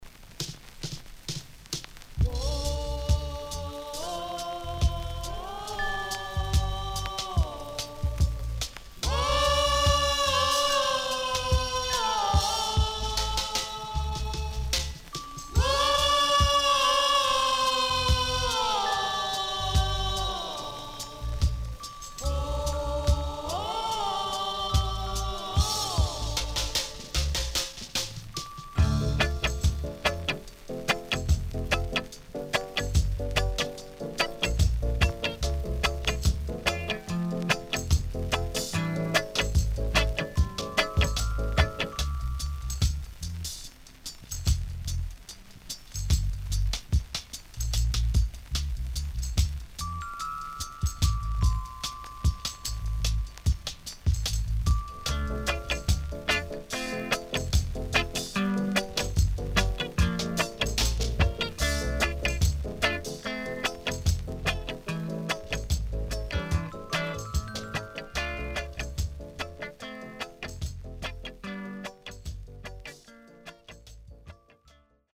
HOME > REGGAE / ROOTS  >  KILLER & DEEP  >  RECOMMEND 70's
Very Rare.Killer & Dubwise.Recommend!!.Pressnoise
SIDE A:この盤特有のプレス起因によるノイズ入ります。